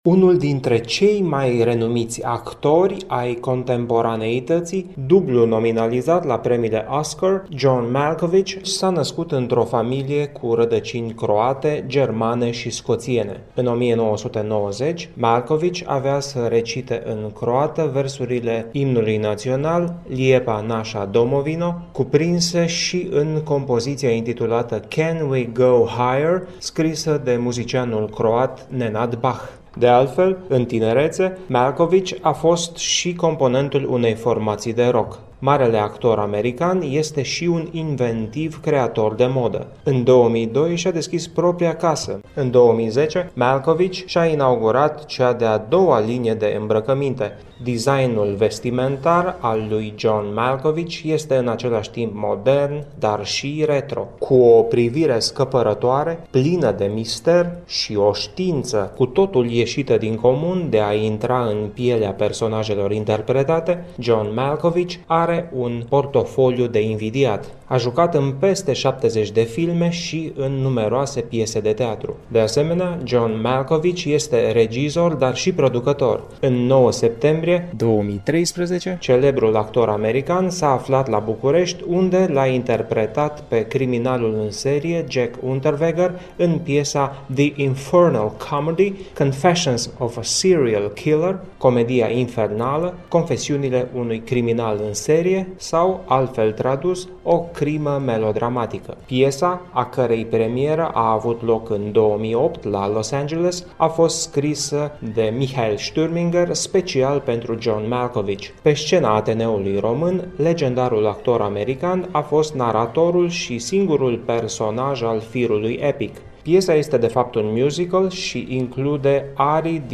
(extras din conferința de presă, 9 septembrie 2013, Festivalul Internațional „George Enescu”)